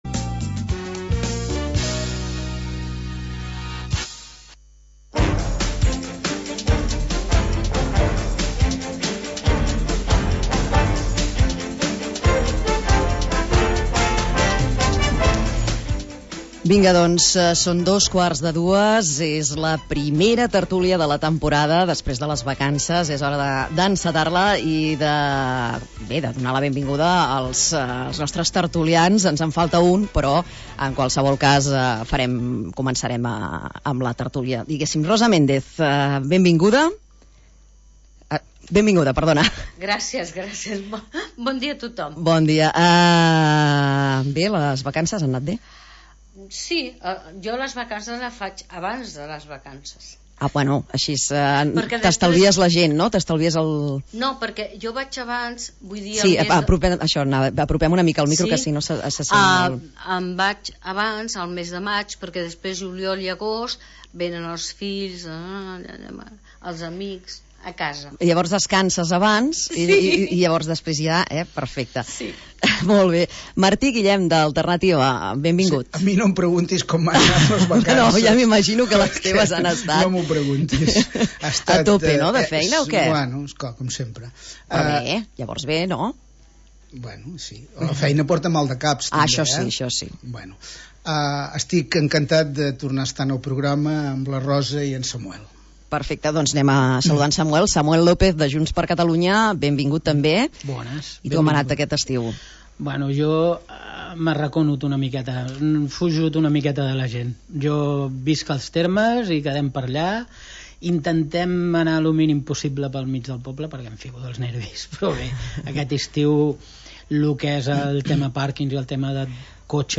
Tertúlia de caire polític